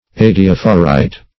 Search Result for " adiaphorite" : The Collaborative International Dictionary of English v.0.48: Adiaphorite \Ad`i*aph"o*rite\ ([a^]d`[i^]*[a^]f"[-o]*r[imac]t), n. Same as Adiaphorist .